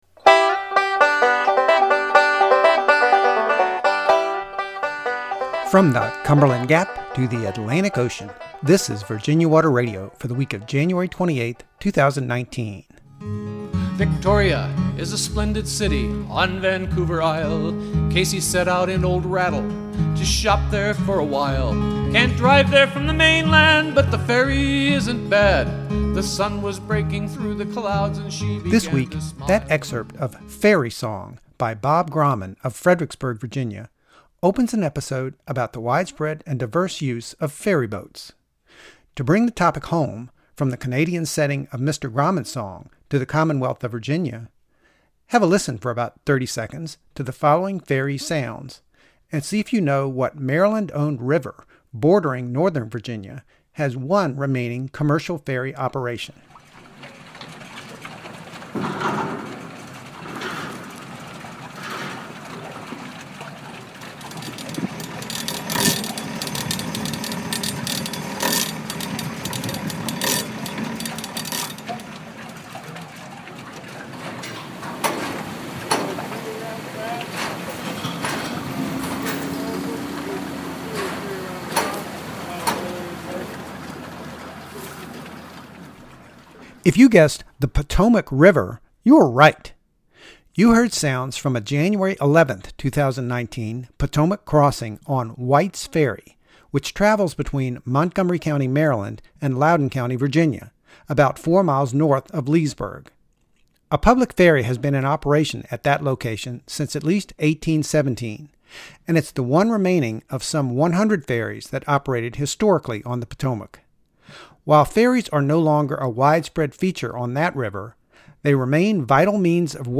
Scenes from White’s Ferry onshore in Montgomery County, Md., and on the Potomac River, January 11, 2019.